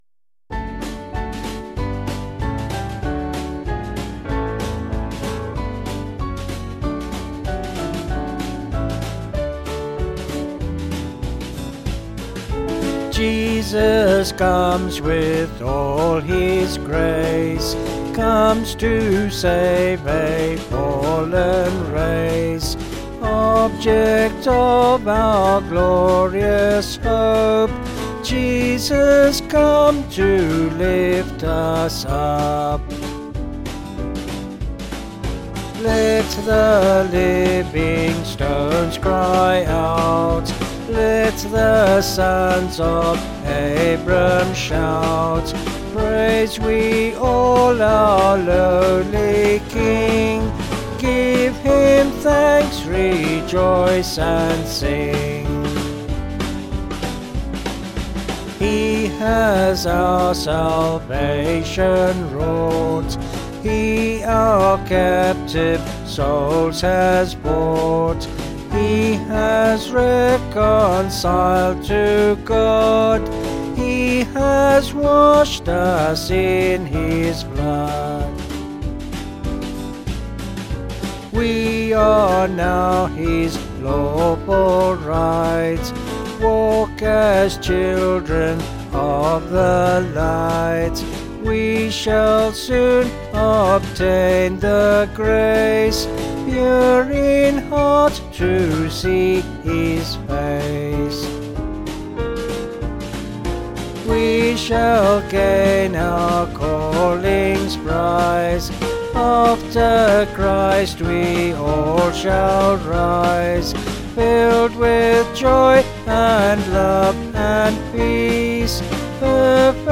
(BH)   5/D-Eb
Vocals and Band   264.2kb Sung Lyrics